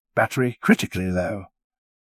battery-critically-low.wav